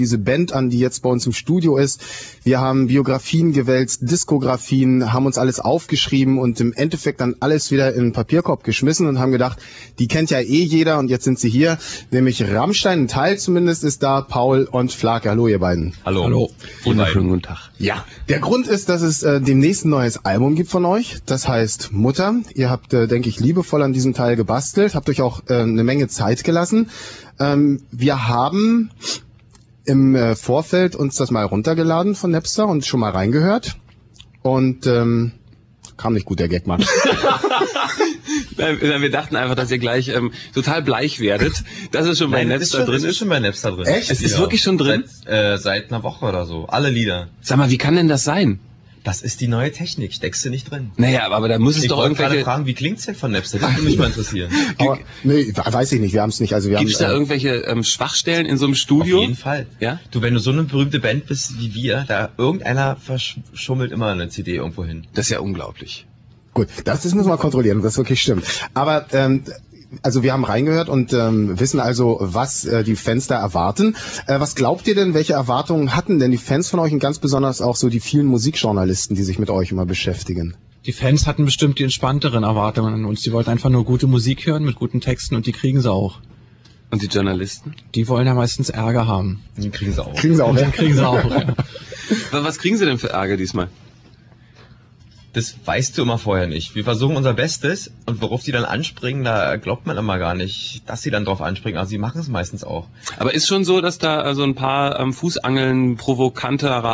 Till, Paul & Flake Interview -
Interview |
Radio_Fritz_Till_Paul_Flake_Interview.rm